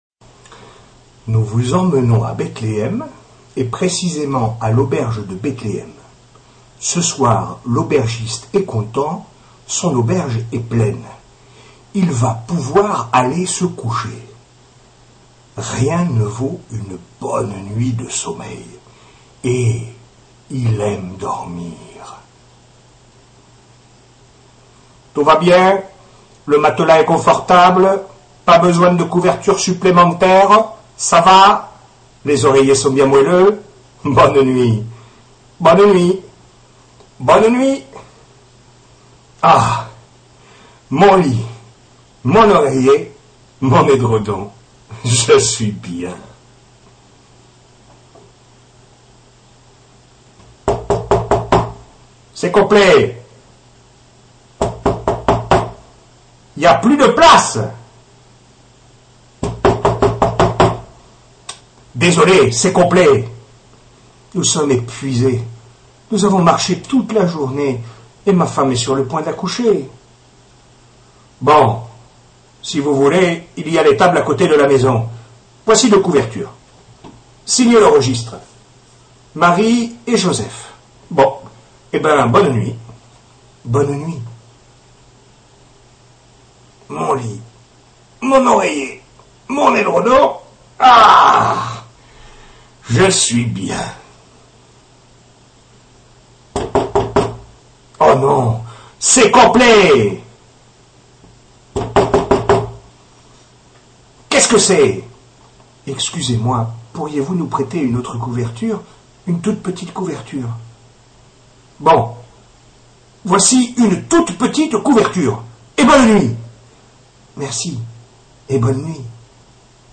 Ecouter le conte de la folle nuit de l'aubergiste